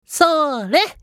少年系ボイス～戦闘ボイス～
【攻撃（強）1】